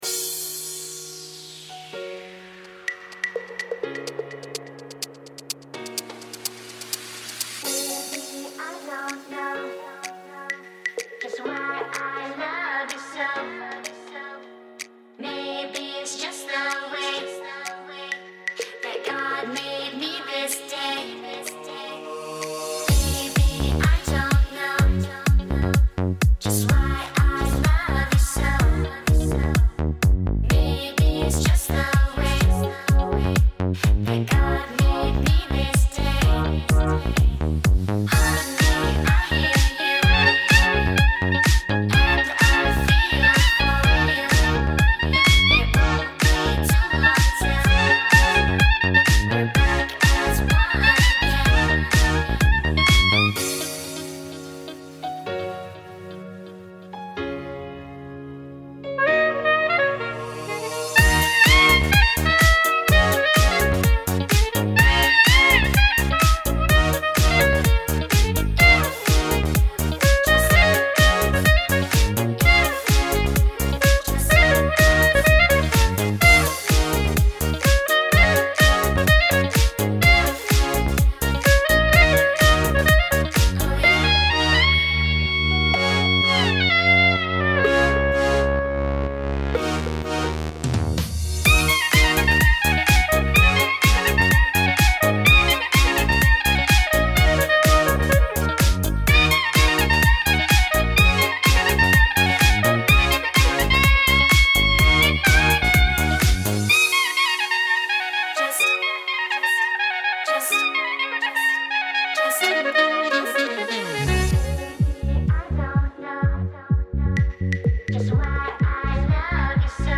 a bit of Ibiza